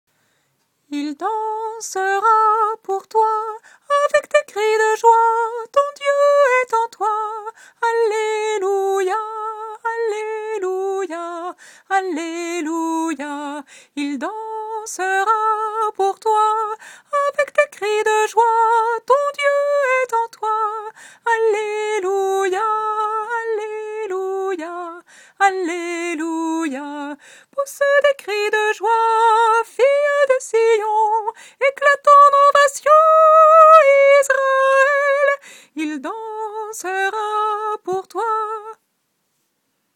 Voix chantée (MP3)COUPLET/REFRAIN
SOPRANE